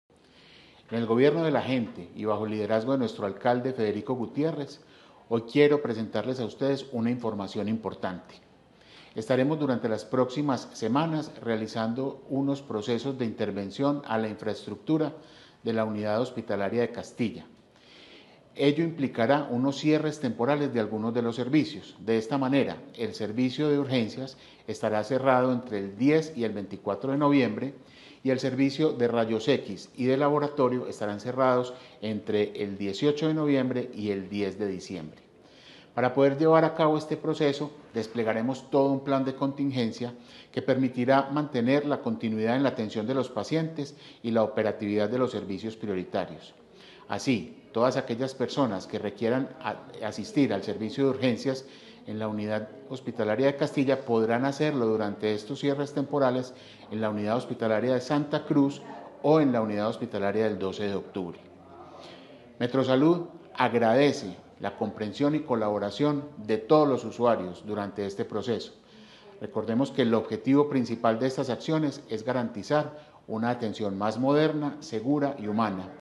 Metrosalud activará un plan de contingencia para garantizar la continuidad en la atención y orientar a los usuarios hacia otras unidades de la red. Declaraciones